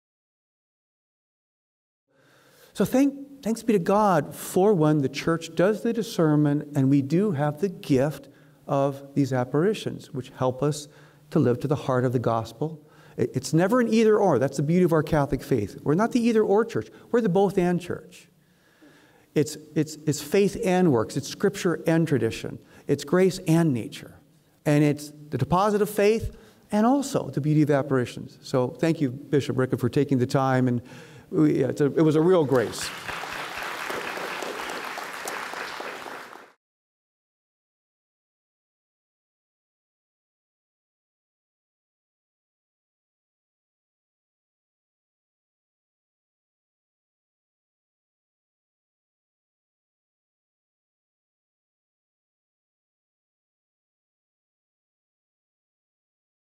Symposium of the International Marian Association